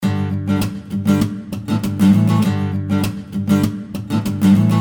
Free MP3 acoustic guitars loops & sounds 4
Guitars Soundbank